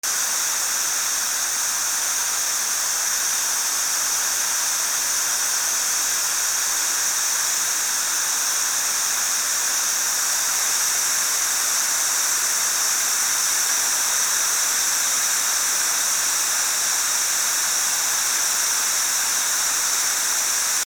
掃除機
吸引音 『キュシューー』